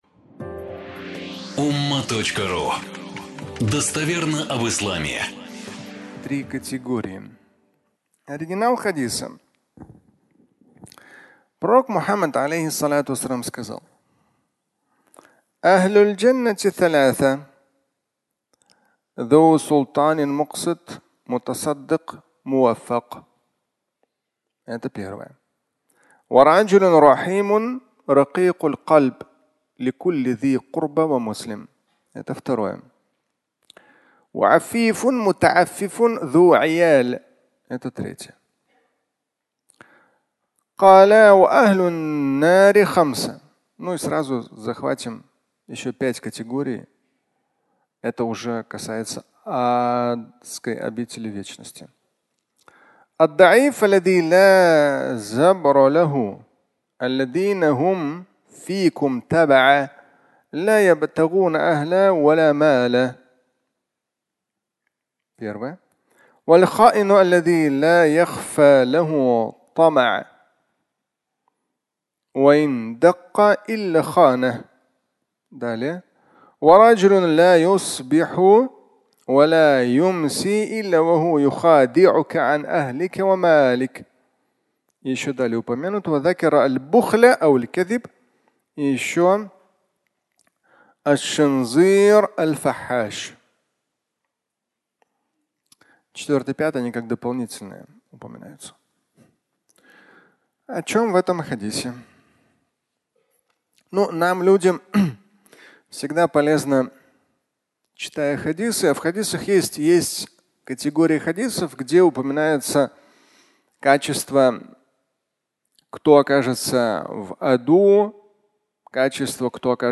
Три категории (аудиолекция)